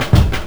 FILLDRUM06-R.wav